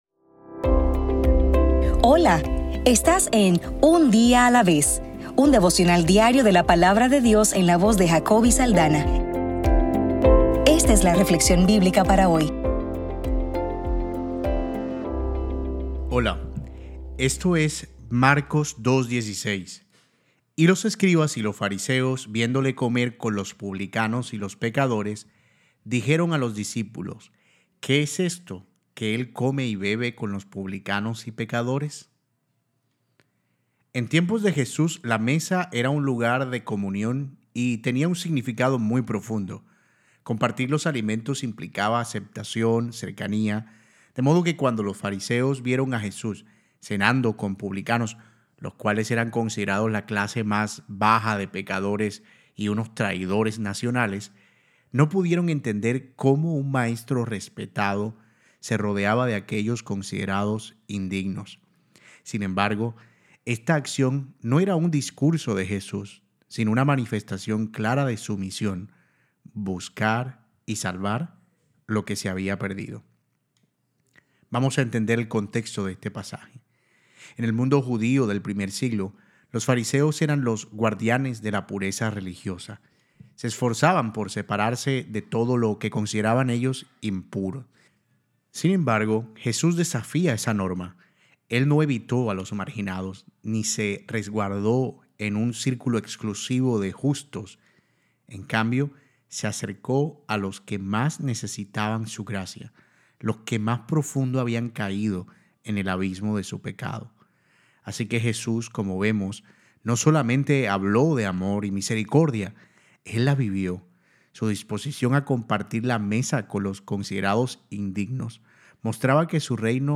Devocional para el 30 de enero